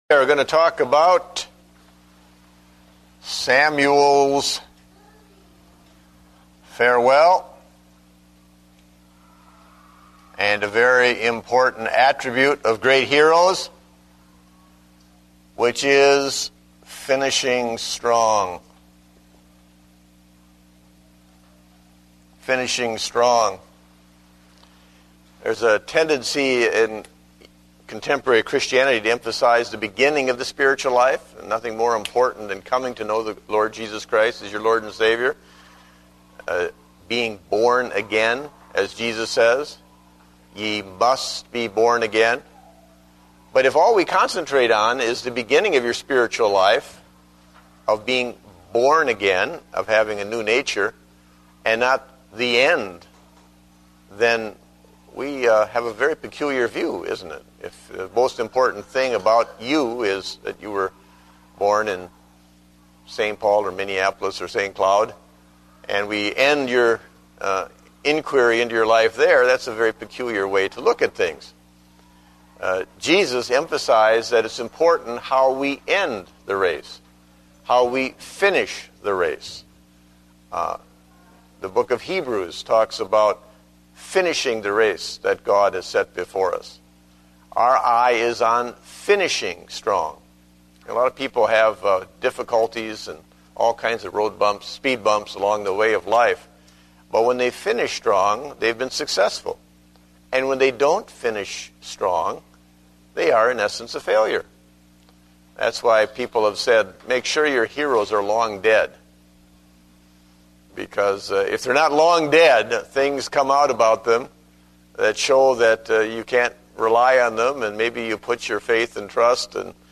Date: September 27, 2009 (Adult Sunday School)